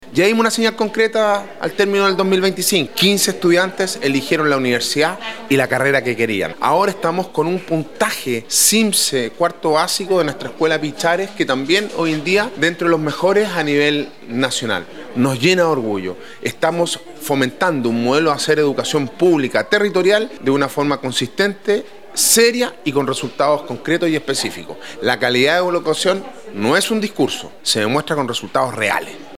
Alcalde-Sebastian-Alvarez-resume-logros-en-la-educacion-municipal.mp3